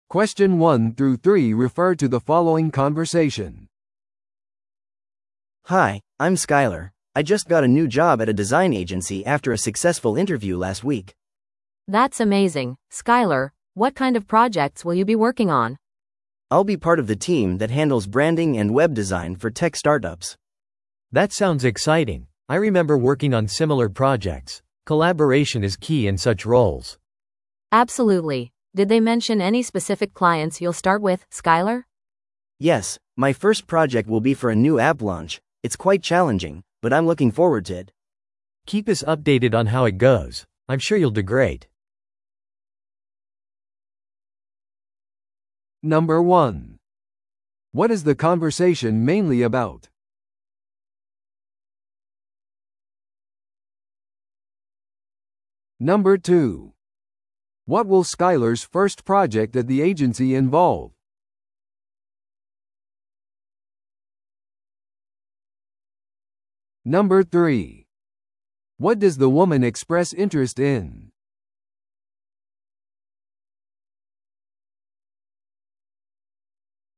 No.1. What is the conversation mainly about?